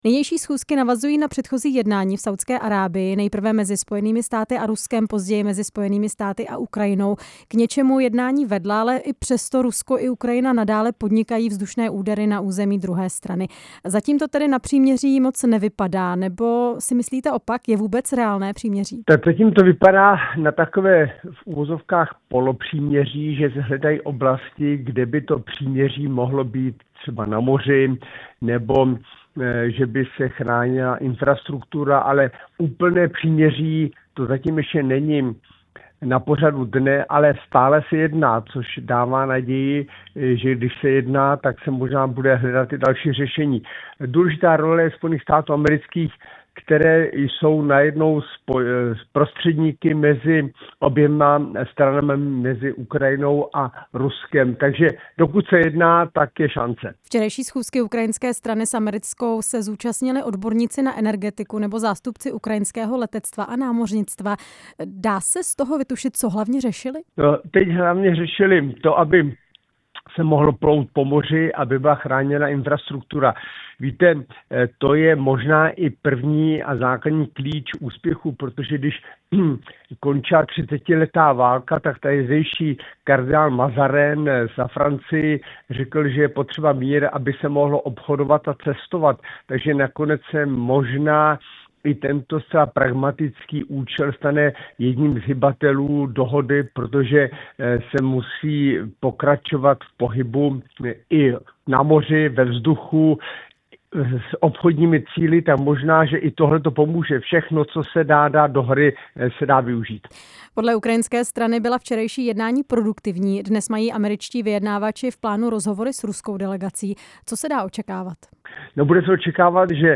V pondělí čeká americké vyjednavače schůzka se zástupci Ruska, které proti Ukrajině válku vede už déle než tři roky. Téma jsme ve vysílání Rádia Prostor probírali s bývalým ministrem zahraničí Cyrilem Svobodou.
Rozhovor s exministrem zahraničí Cyrilem Svobodou